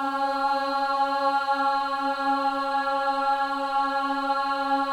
LC AH C#4F.wav